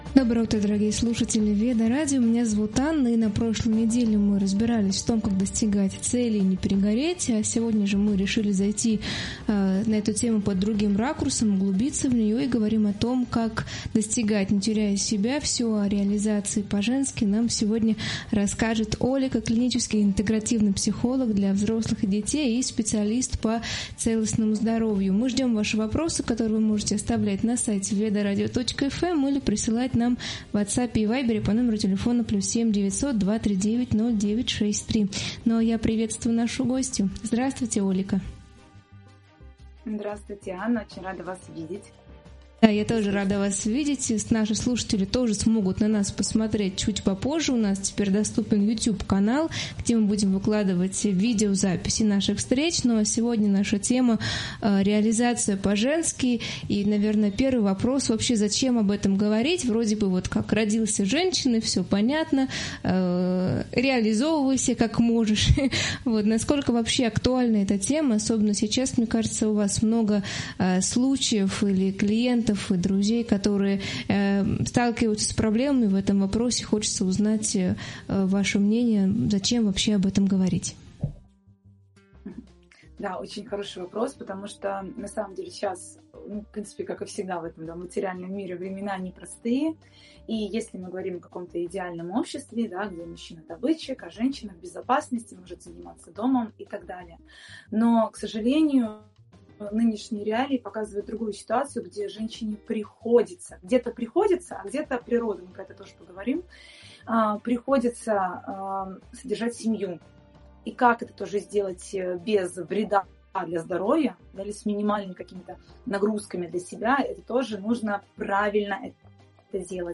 Обсуждение о том, как достигать целей и не перегореть.